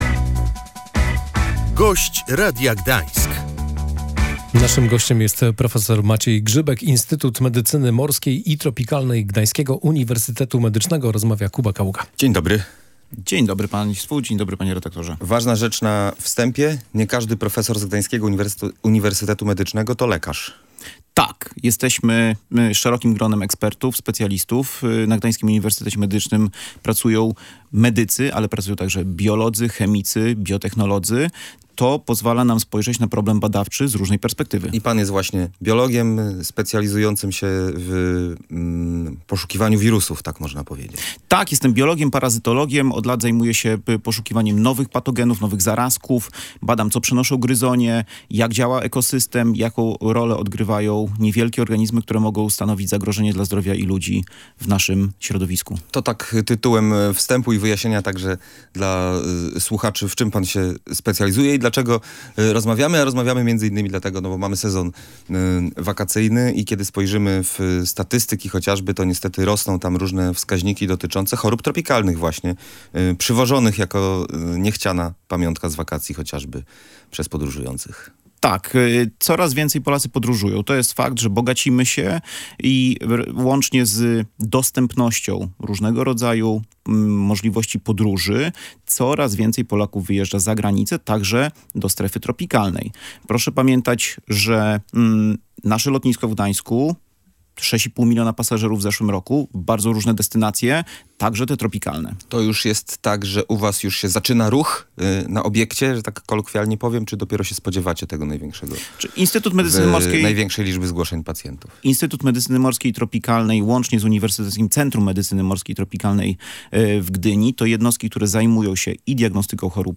Czeka nas redefinicja tego, czym jest choroba tropikalna – stwierdził Gość Radia Gdańsk.